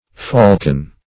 faulcon - definition of faulcon - synonyms, pronunciation, spelling from Free Dictionary Search Result for " faulcon" : The Collaborative International Dictionary of English v.0.48: Faulcon \Faul"con\, n. (Zool.)